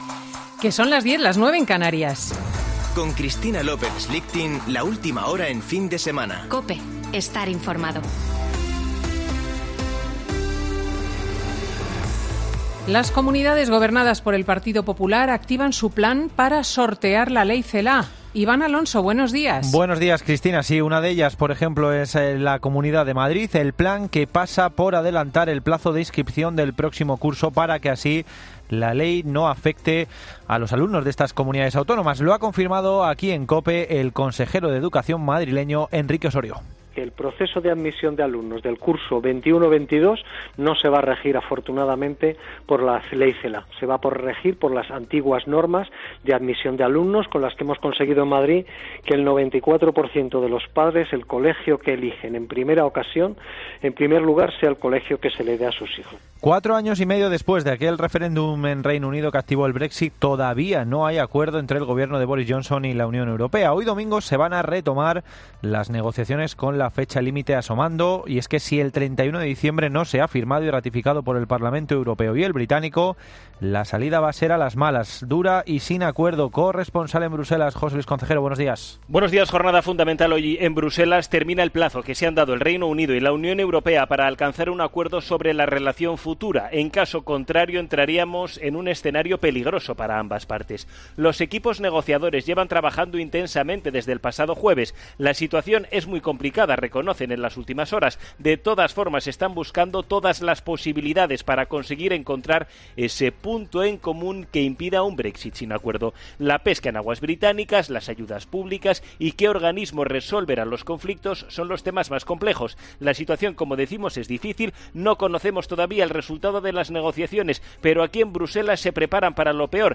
Boletín de noticias COPE del 13 de diciembre de 2020 a las 10.00 horas